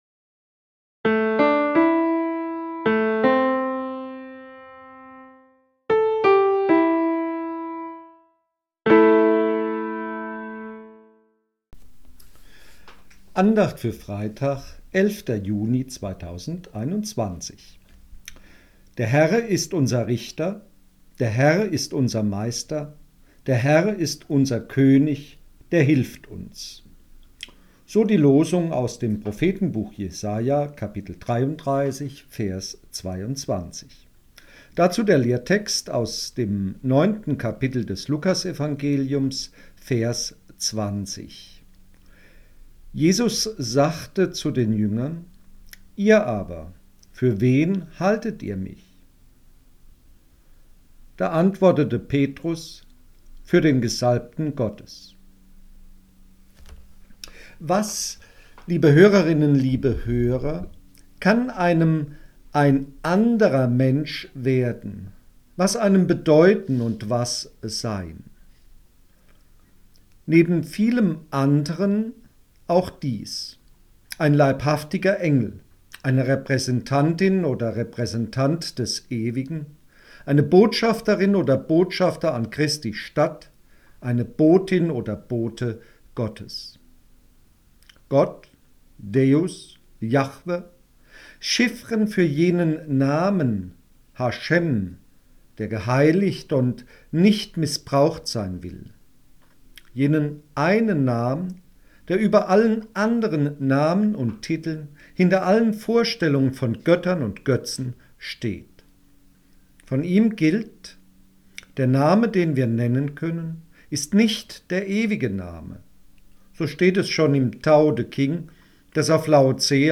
Losungsandacht für Freitag, 11.06.2021